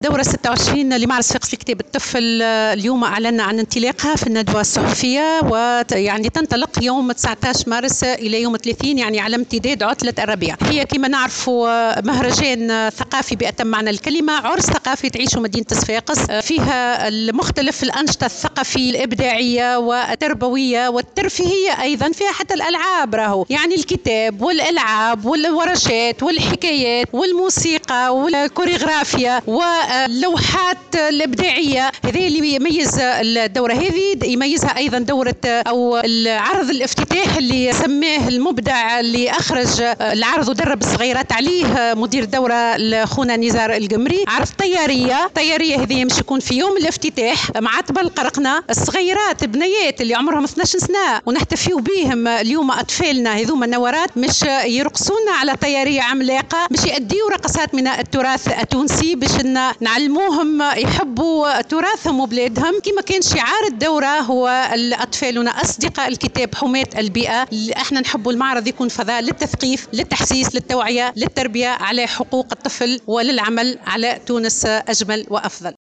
تفاصيل الندوة الصحفية الخاصة بمعرض صفاقس لكتاب الطفل (تصريح)